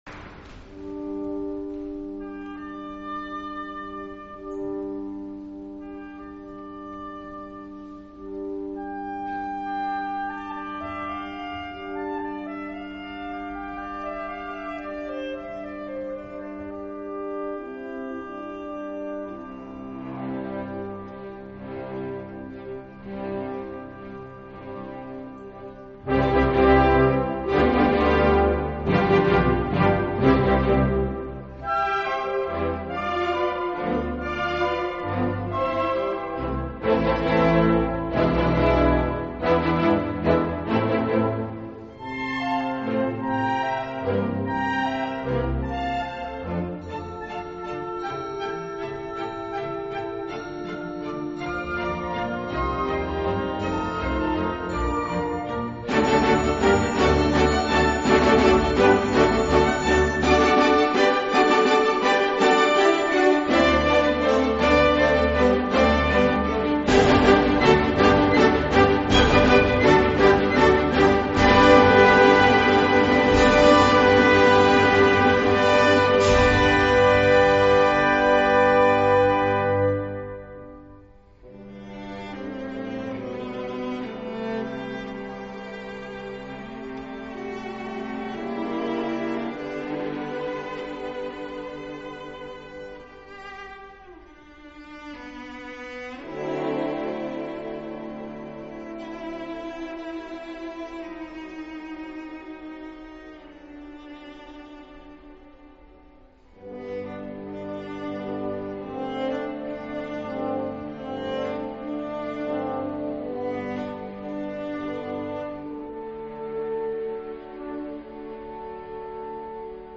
Pizzicato Polka 拨弦波尔卡 19.